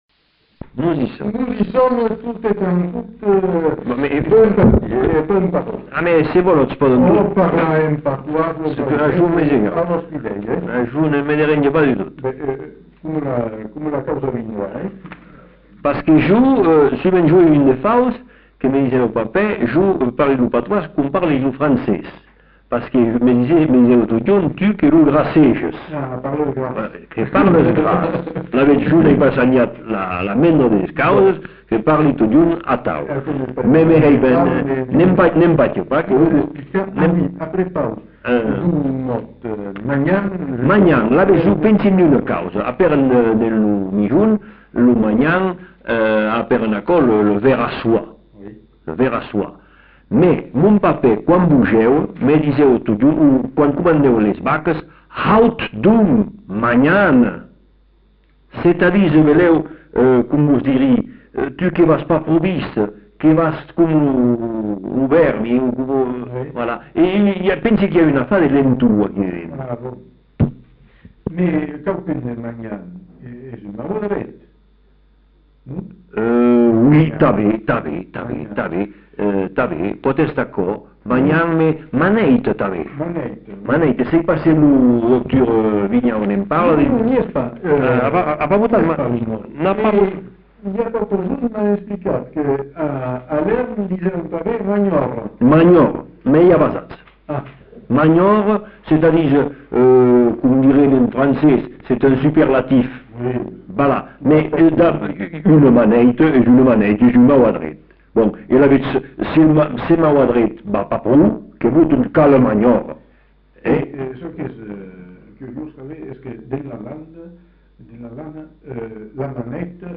Lieu : Bazas
Genre : témoignage thématique
[enquêtes sonores] Vocabulaire occitan avec précisions sur les usages et les coutumes